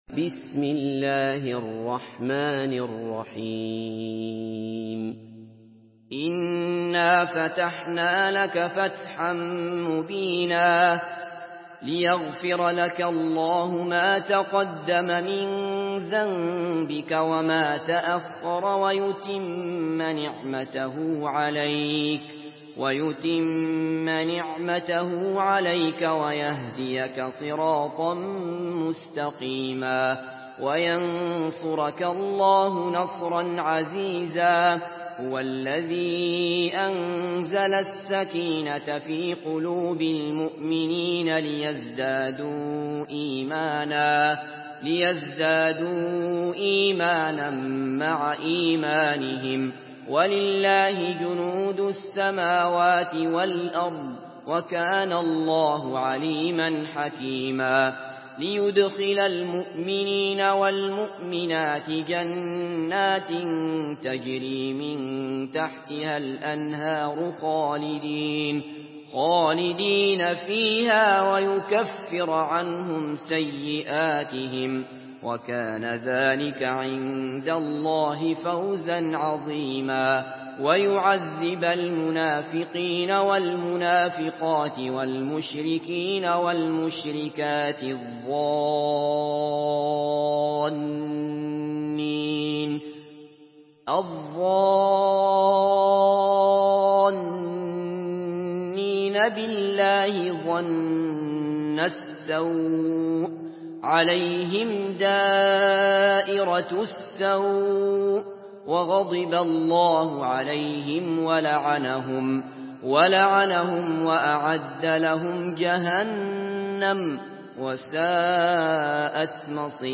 Surah আল-ফাতহ MP3 by Abdullah Basfar in Hafs An Asim narration.
Murattal Hafs An Asim